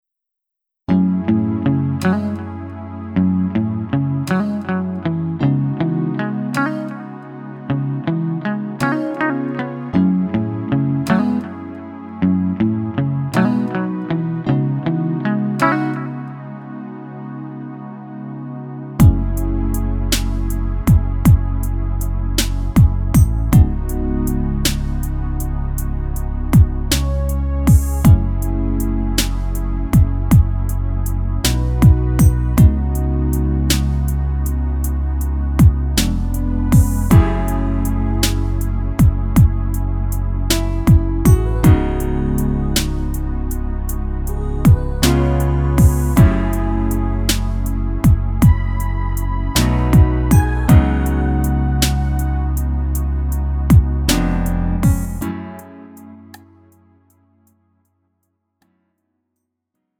음정 원키 3:07
장르 가요 구분 Lite MR